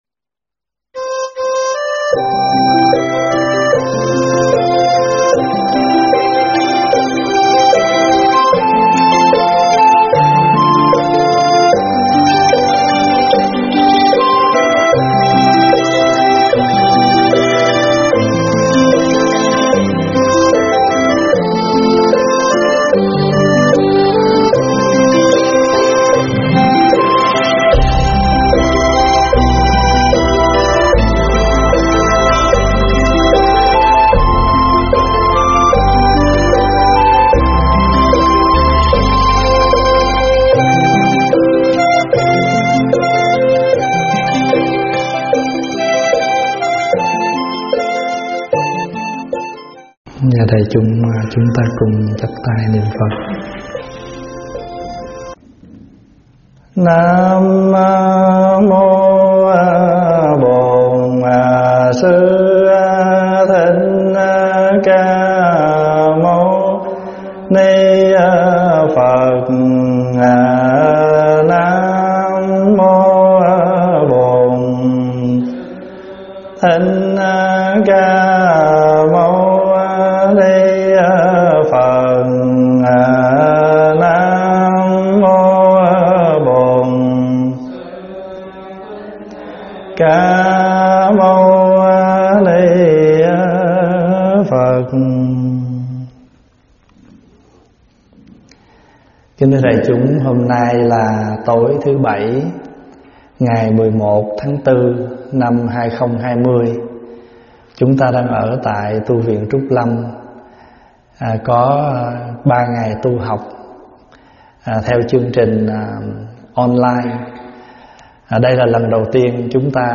Thuyết pháp Xe Báu Đại Thừa 8